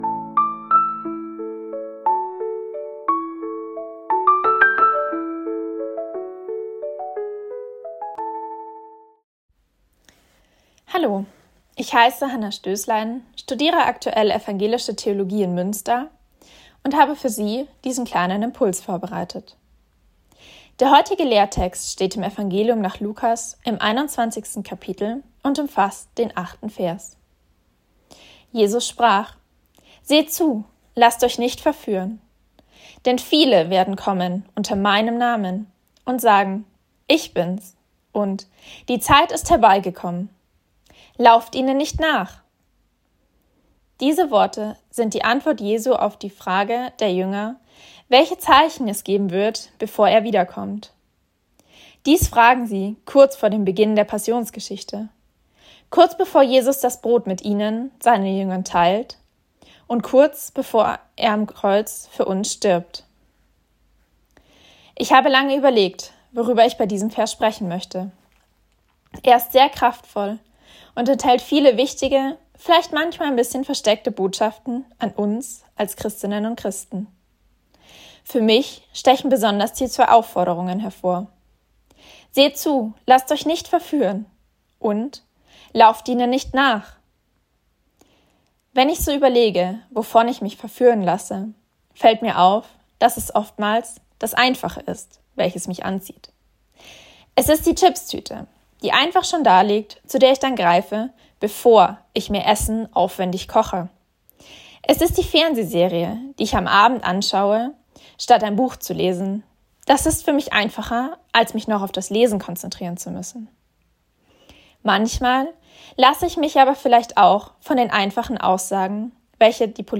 Losungsandacht für Donnerstag, 21.08.2025